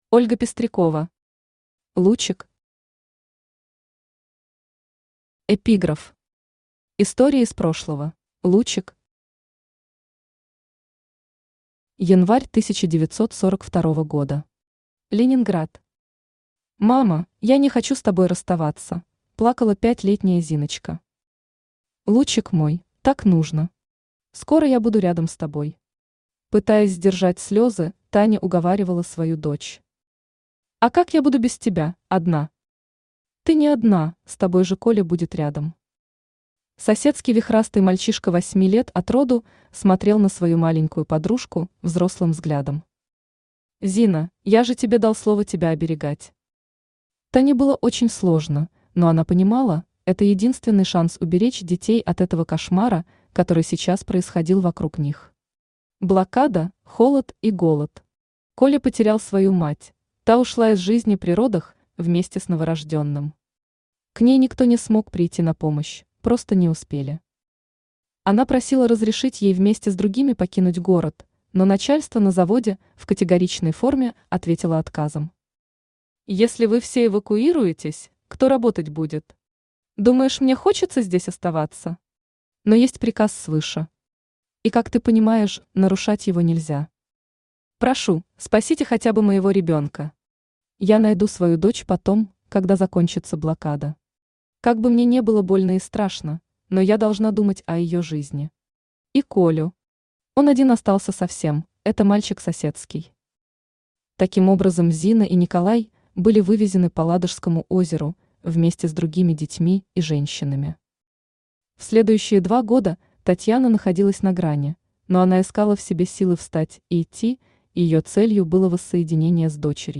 Аудиокнига Лучик | Библиотека аудиокниг
Aудиокнига Лучик Автор Ольга Владимировна Пестрякова Читает аудиокнигу Авточтец ЛитРес.